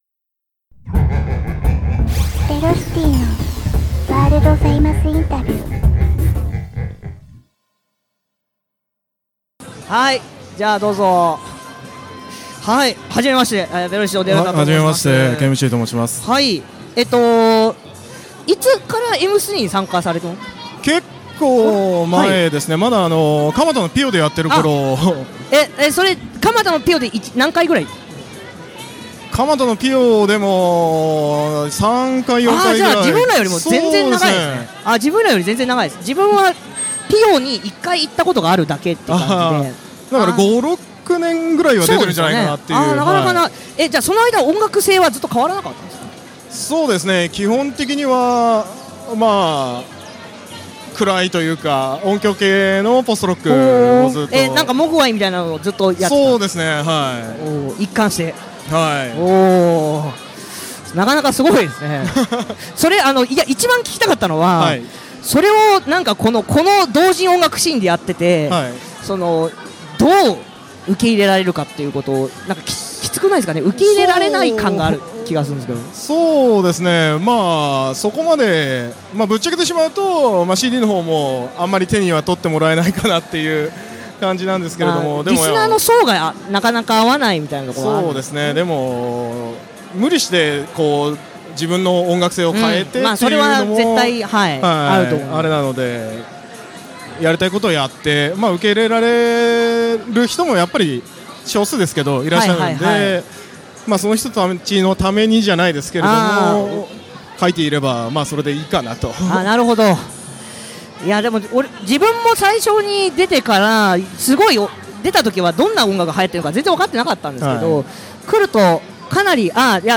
フリースペースにて他サークルへのインタビュー企画を実施！
interview_apazome.mp3